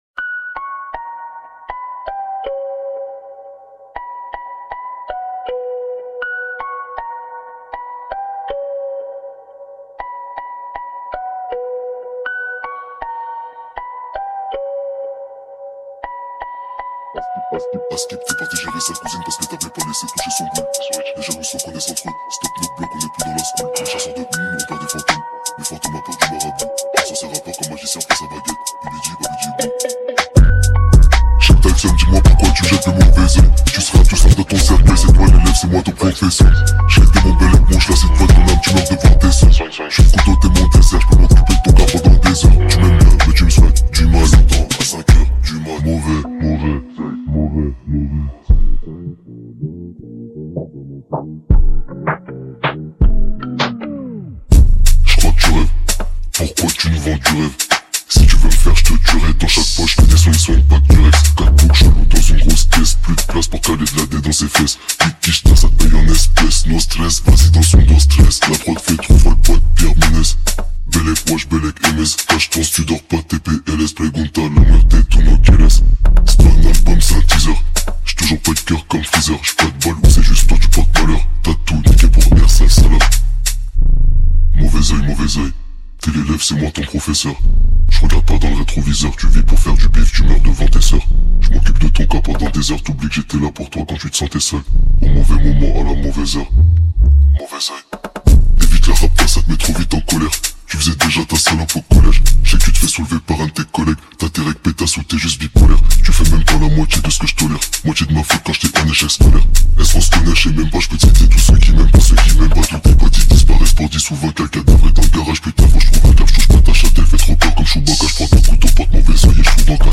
slowed + bass boosted